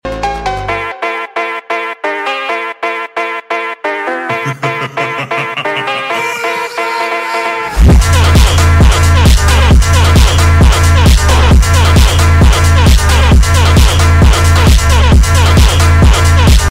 Round 1: Fight! sound effects free download